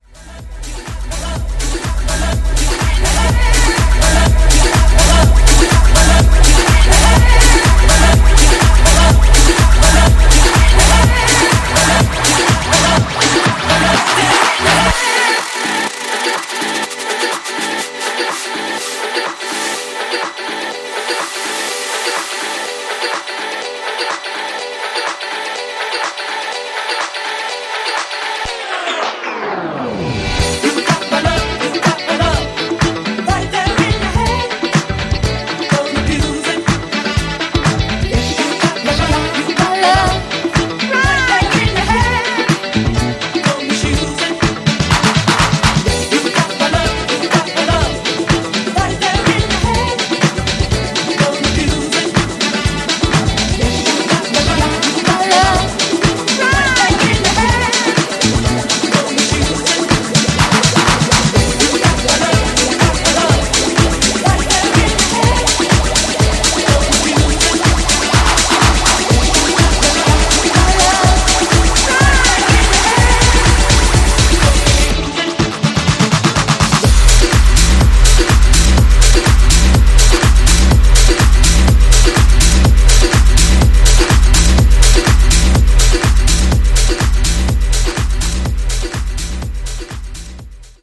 ジャンル(スタイル) DISCO HOUSE / EDITS